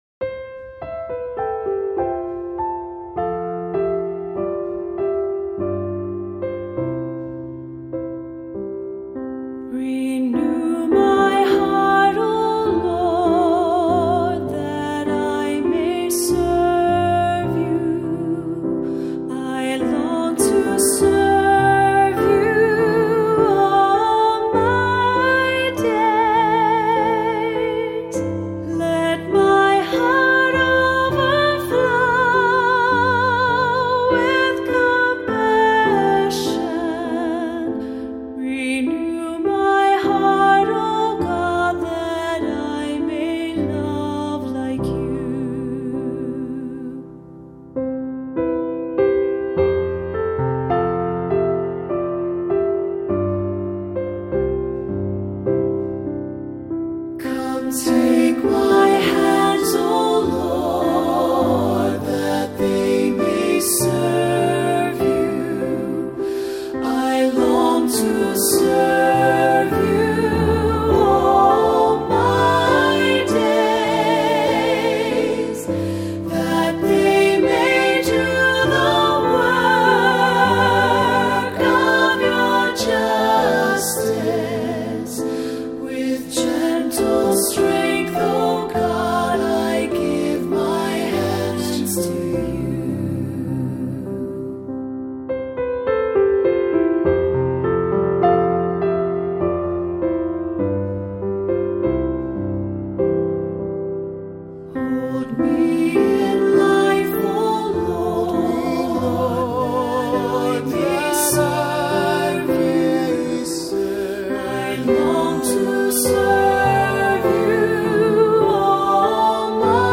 Voicing: SATB; Descant; Assembly